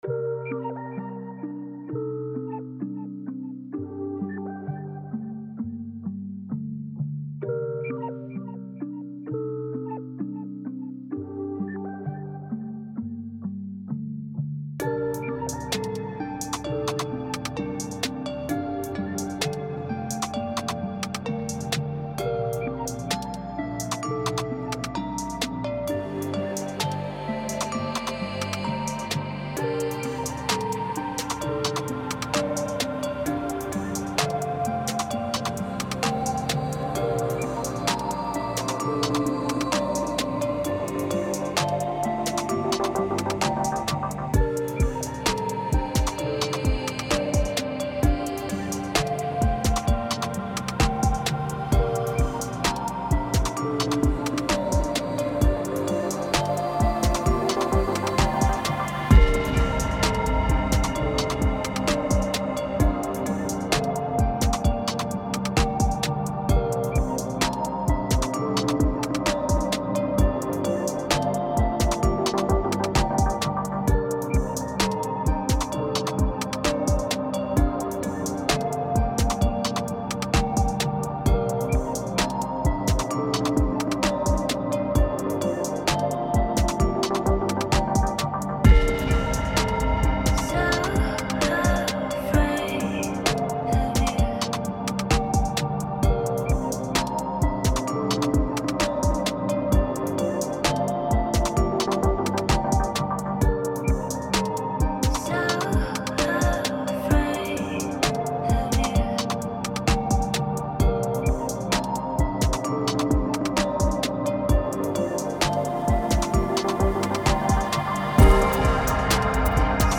• Version Remix
Tempo 130BPM (Allegro)
Genre Dark Trap Chill
Type Vocal Music
Mood Conflicting (Sorrow/Chill)